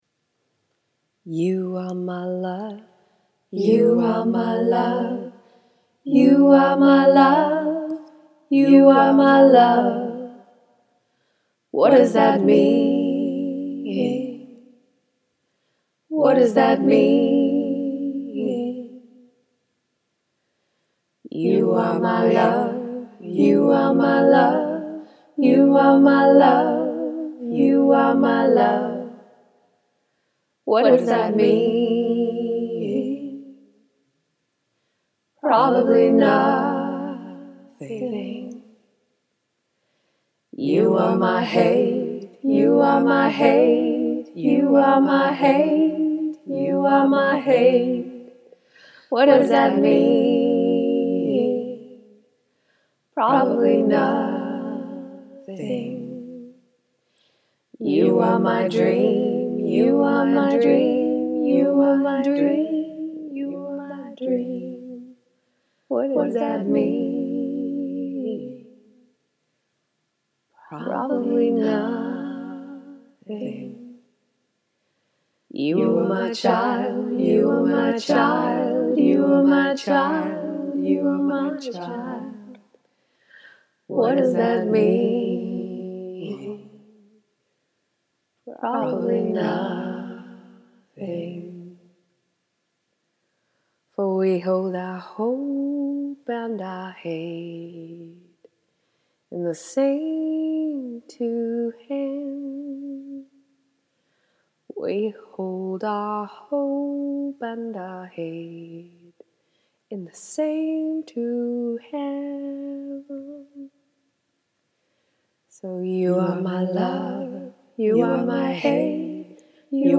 At one point in my life I had a fascination with bike poetry,  sometimes it’s bike songs.  The rules of engagement are fairly simple,  I ride my bike, this is what I sing in my head and then I give it 10 or 15 min when I get time to record it so that I can sing it on my next bike ride.